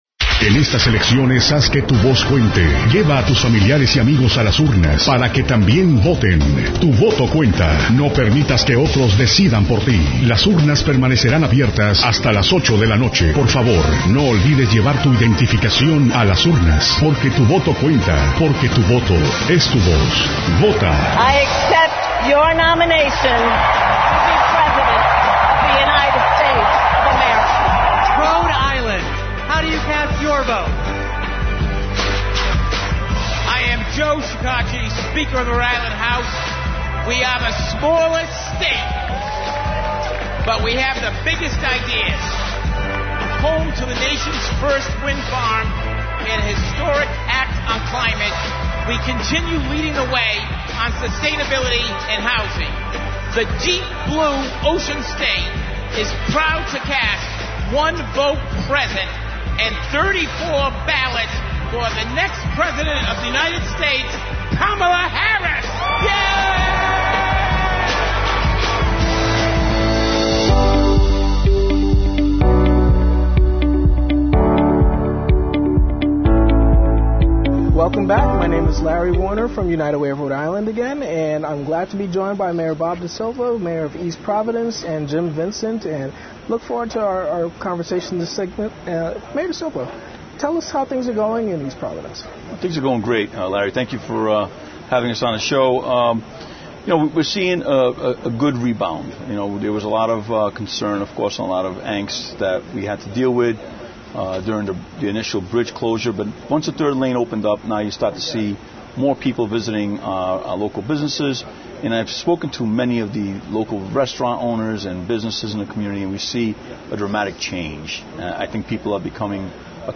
Mayor of East Providence, Roberto “Bob” L. DaSilva at LPR’s Political BBQ 2024 – LPR News